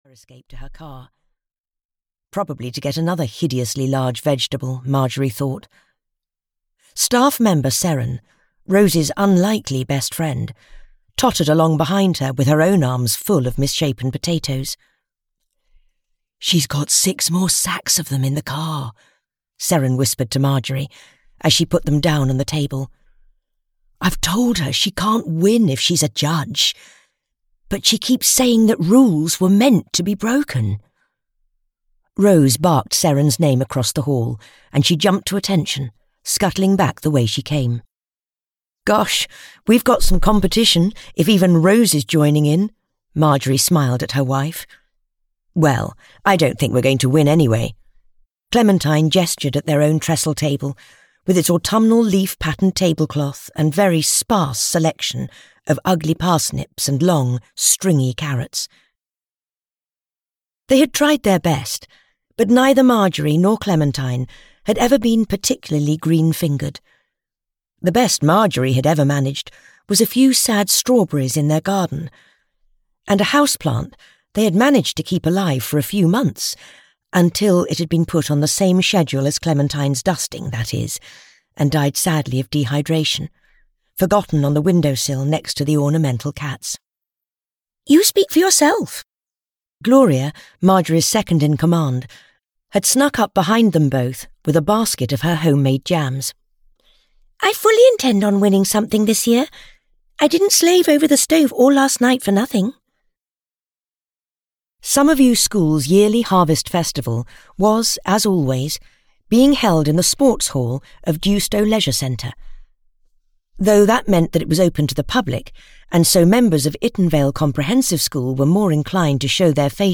A Frightfully Fatal Affair (EN) audiokniha
Ukázka z knihy